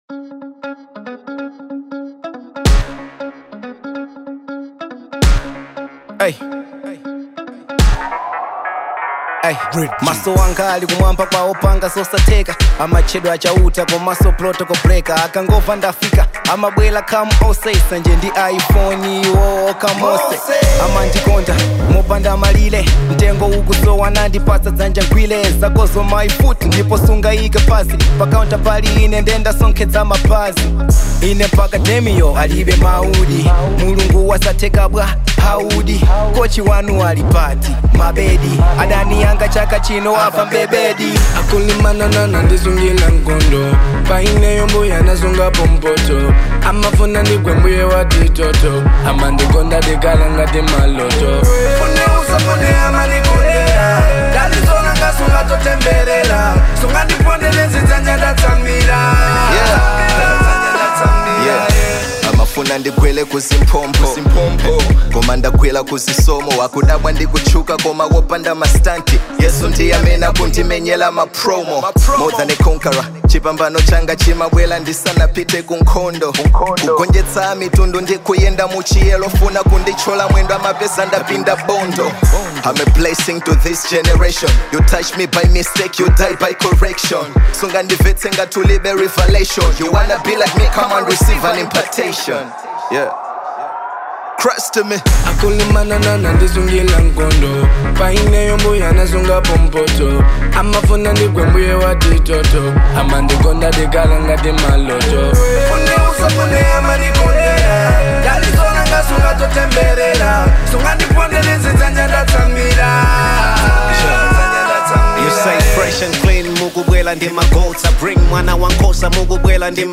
Genre : Gospel